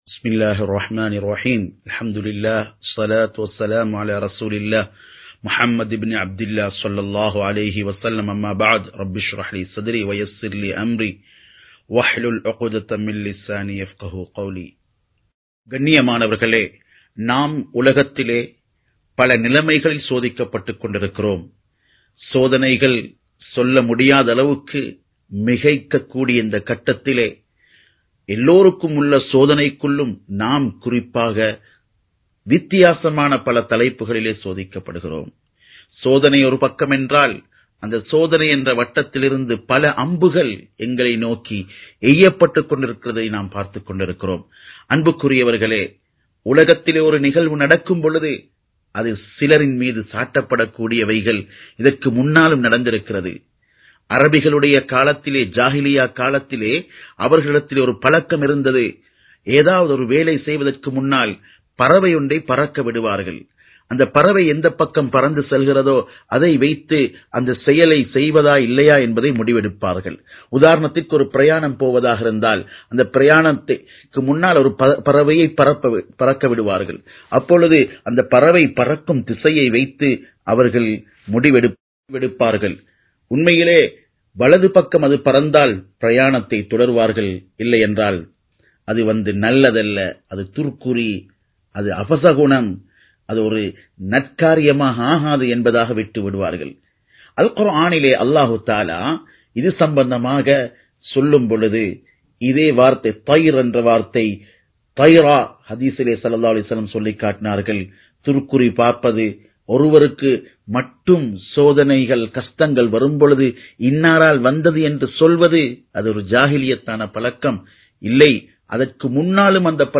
Soathanaihalum ufahaaramum (சோதனைகளும் உபகாரமும்) | Audio Bayans | All Ceylon Muslim Youth Community | Addalaichenai
Live Stream